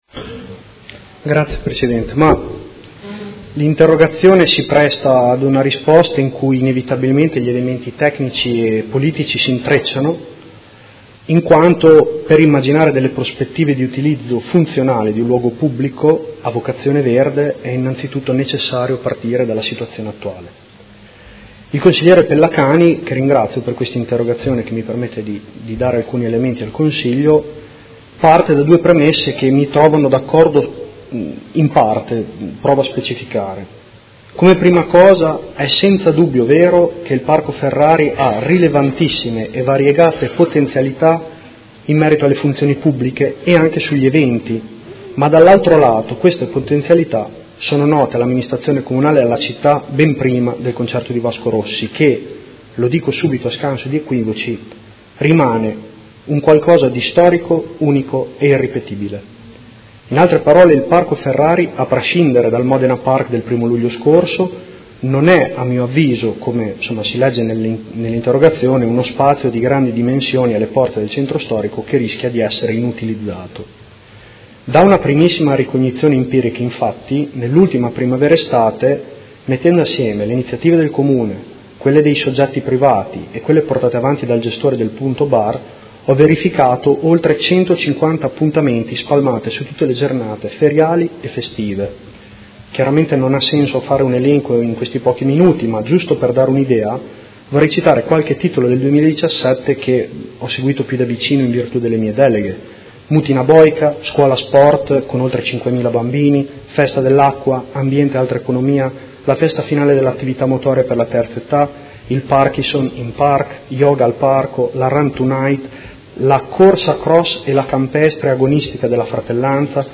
Seduta del 9/11/2017. Risponde a interrogazione del Consigliere Pellacani (FI) avente per oggetto: Dopo Vasco, quali progetti per il Parco Ferrari?